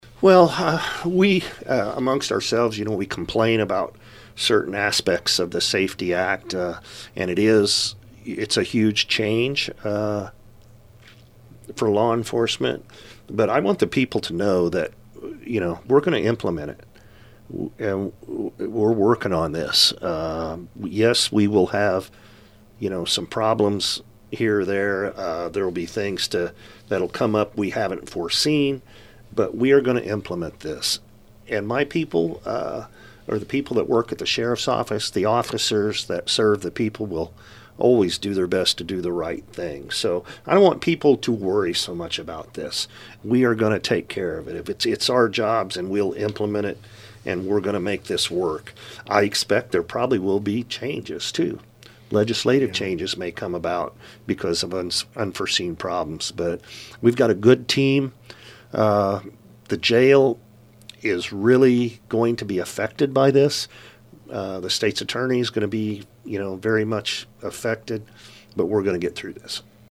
SAFE-T Act Details Explained In Interview With Effingham County Sheriff Paul Kuhns
safe-t-act-interview-part-7.mp3